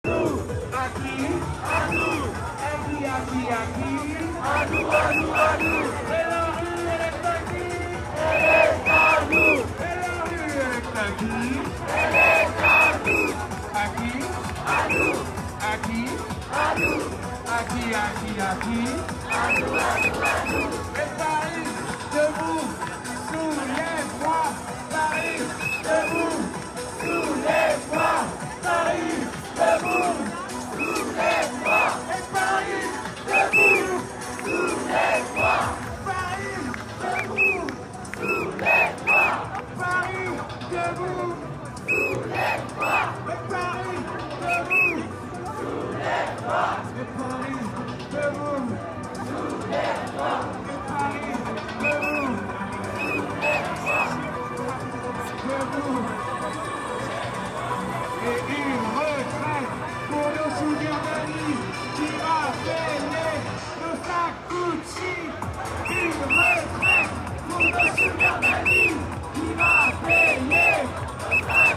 Demonstration in Paris.